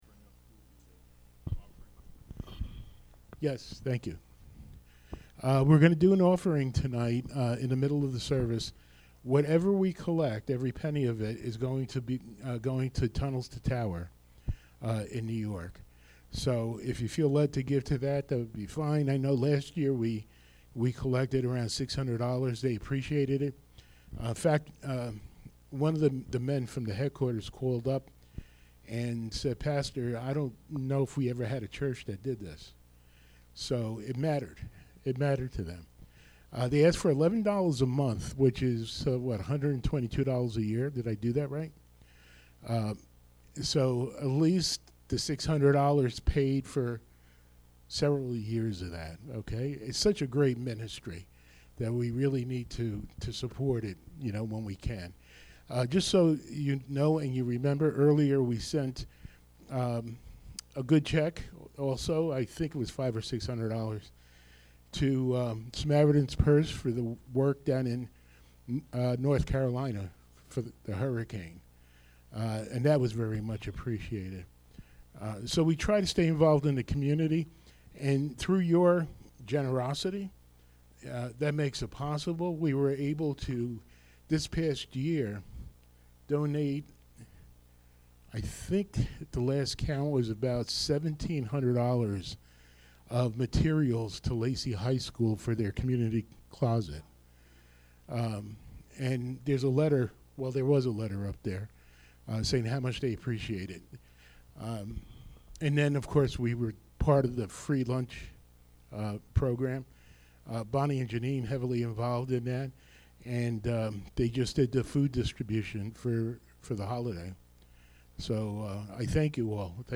Be blessed by our candlelight Christmas Eve service.